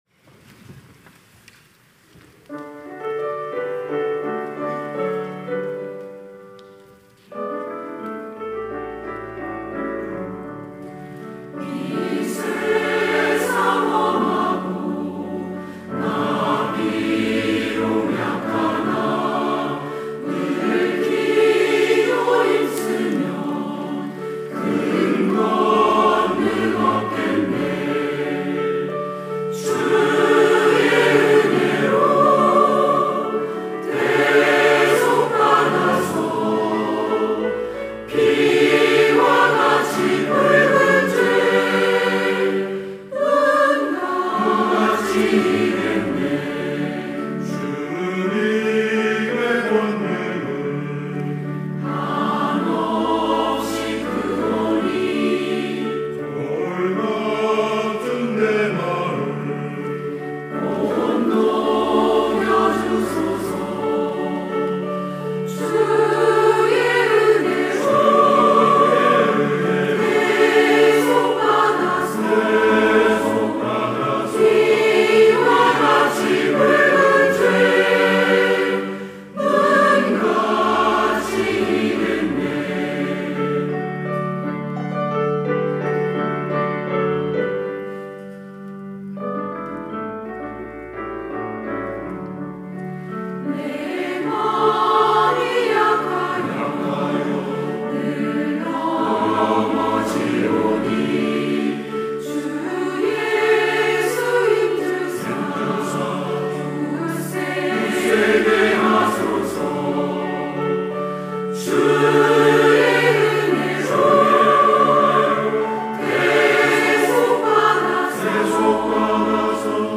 시온(주일1부) - 이 세상 험하고
찬양대 시온